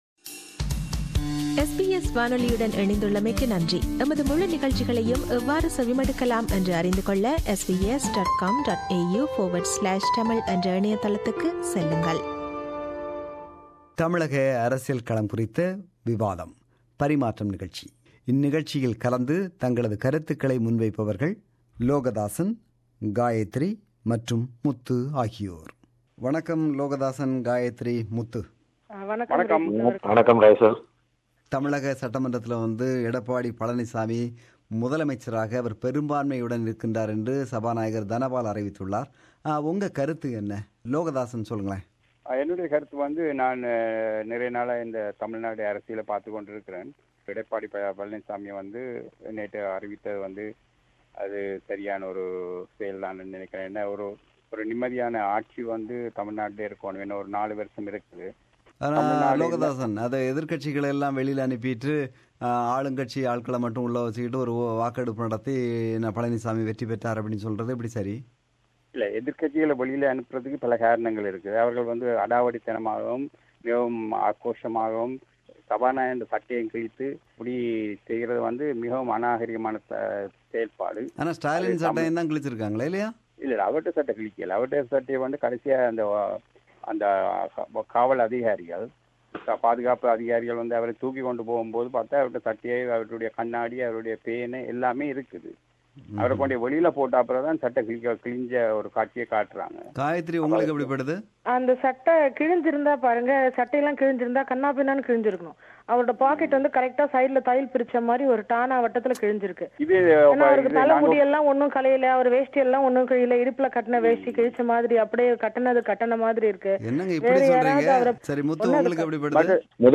Tamil Nadu Politics: A Panel Discussion